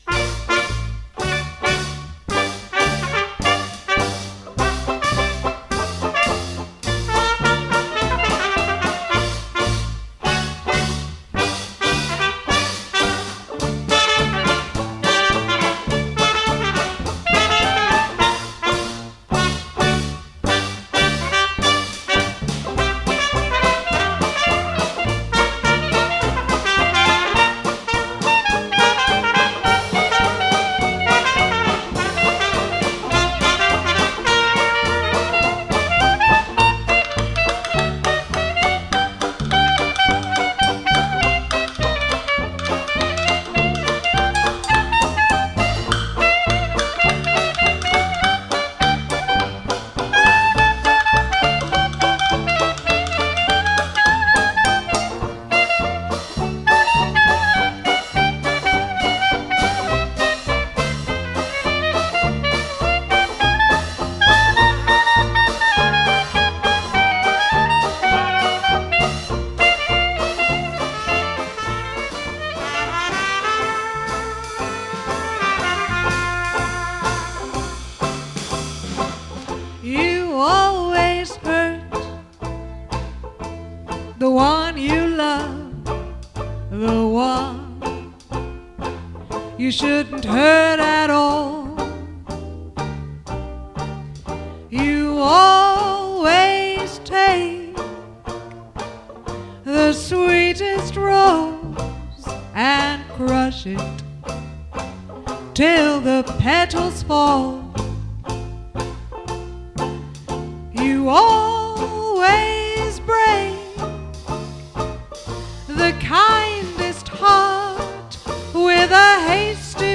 Live music from the 1920s, 30s and 40s
Music from our previous Concert